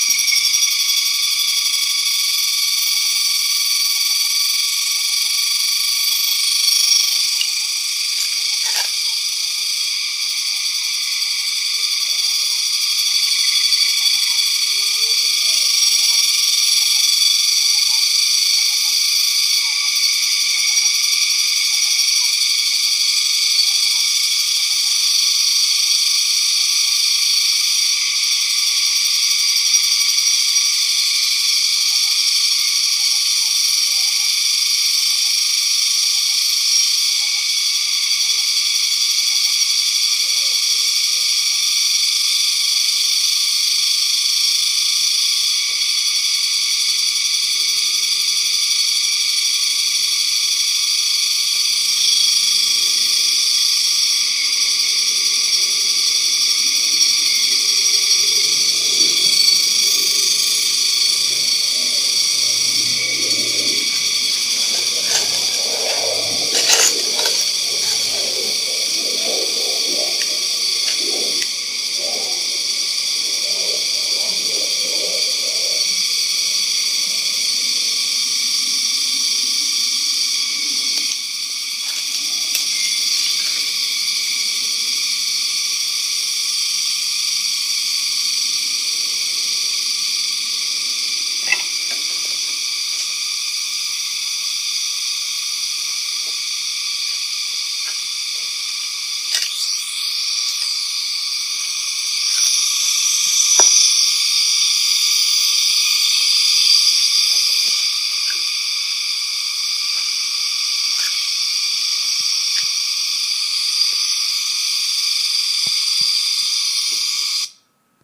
▤s∺✪n≣d waves, the soundscape of every subject surrounding you.